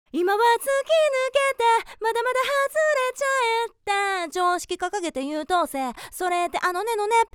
▼学習に使用した音声